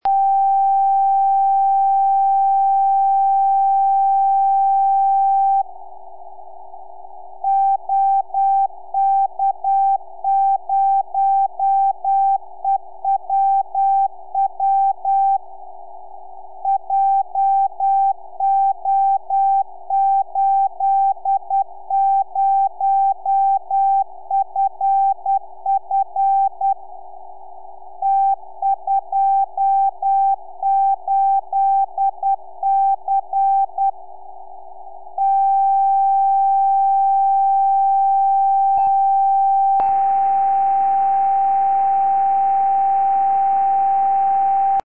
Overview of the Ham Radio Beacons in OK & OM